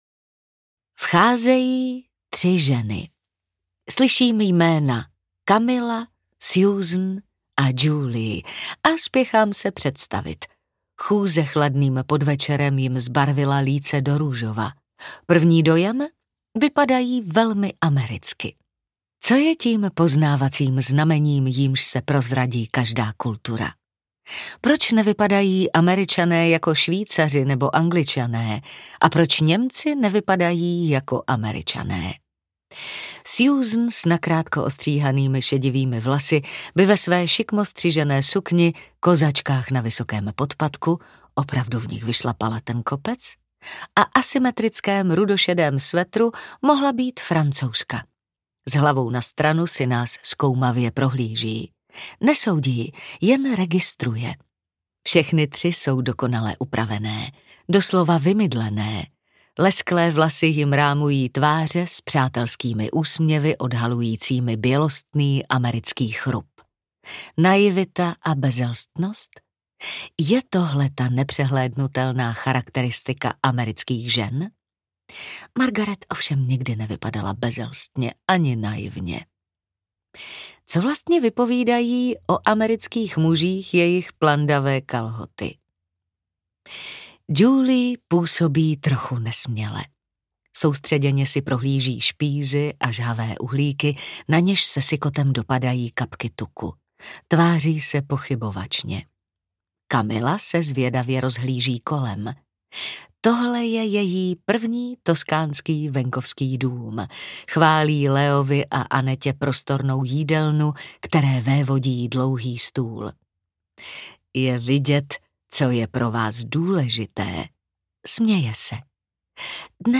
Přítelkyně z kamenné vily audiokniha
Ukázka z knihy
Vyrobilo studio Soundguru.